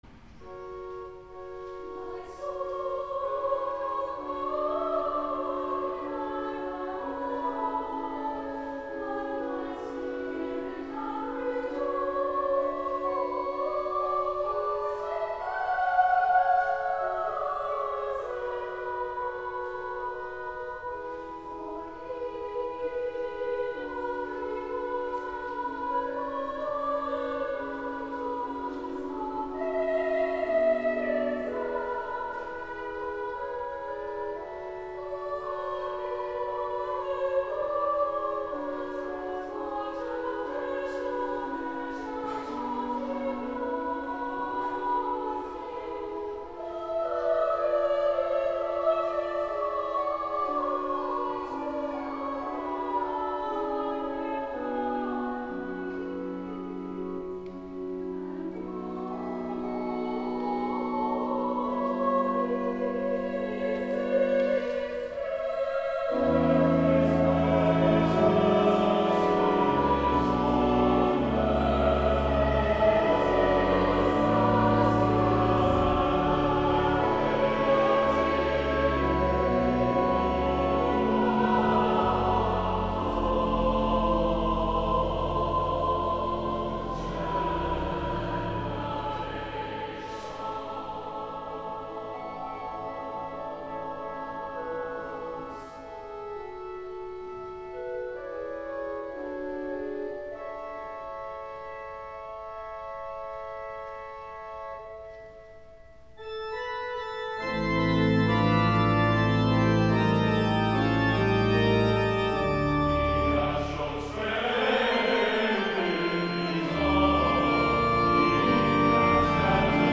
A collection of canticles from St. John's Cathedral, Jacksonville Florida